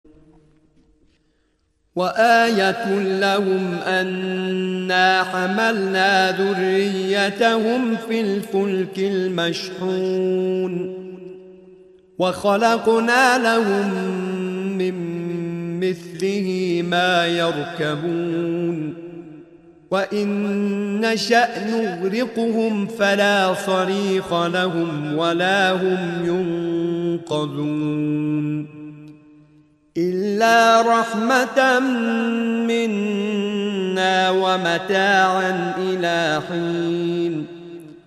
سوره مبارکه یس آیه 41 تا 44/ نام دستگاه موسیقی: چهارگاه